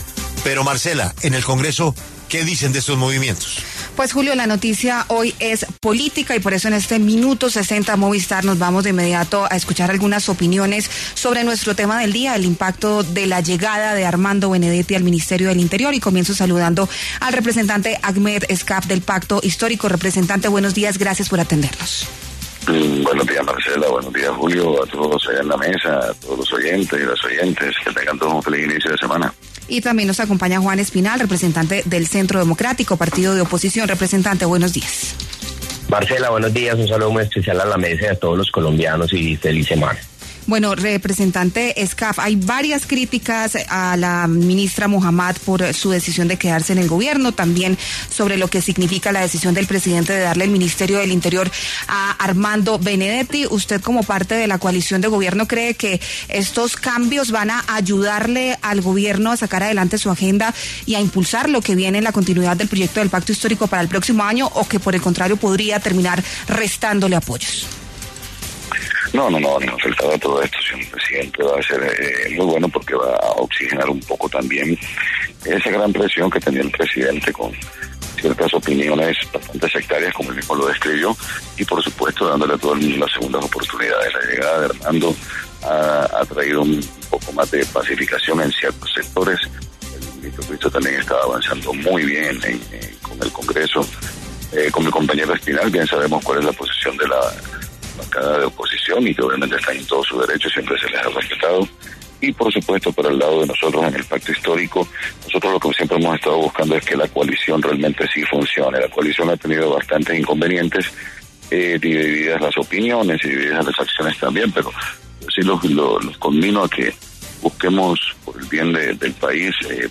Los representantes Agmeth Escaf, del Pacto Histórico, y Juan Espinal, del Centro Democrático, pasaron por los micrófonos de La W.